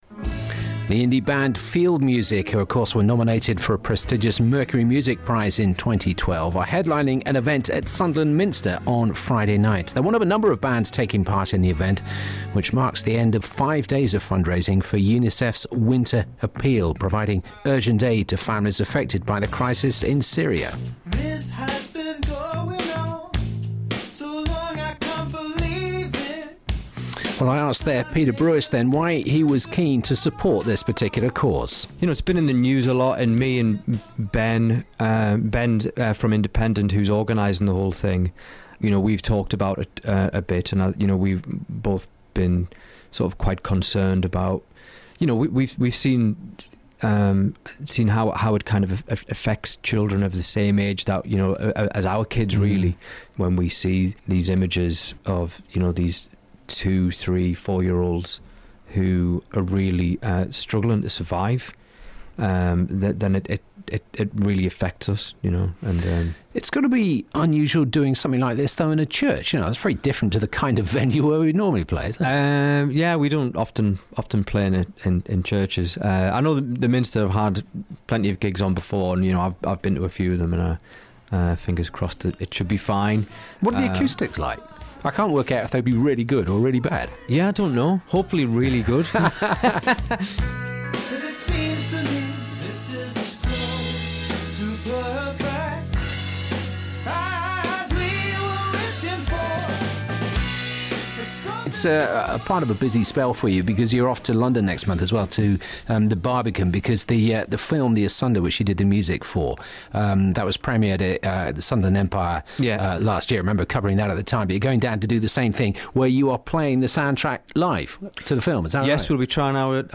FIELD MUSIC INTERVIEW